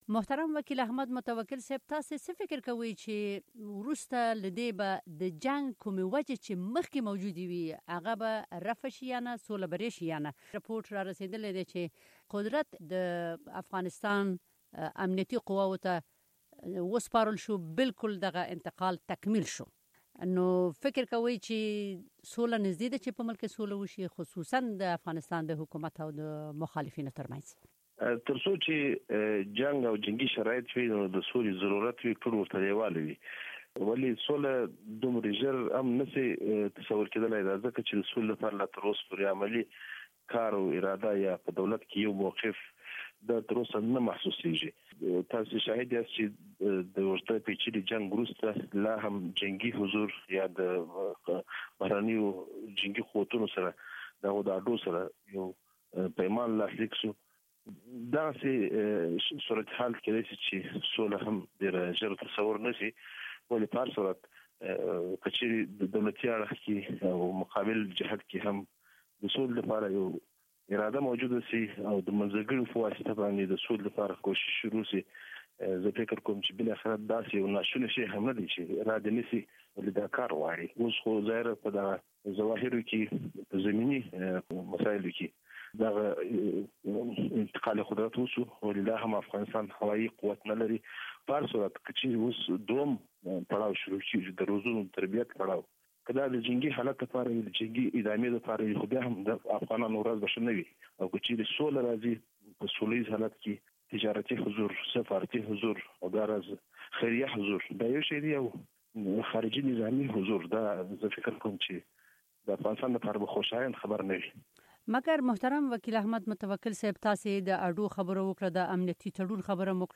د ښاغلی متوکل سره بشپړه مرکه دلته اوریدلی شئ